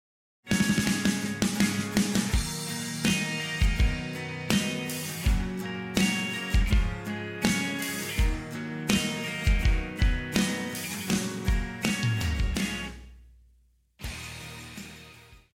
套鼓(架子鼓)
乐团
演奏曲
流行音乐,另类摇滚
独奏与伴奏
有主奏
有节拍器